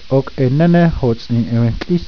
tt-oh kk-ey ney nehne hoh tts-een ney ree-ttl-eese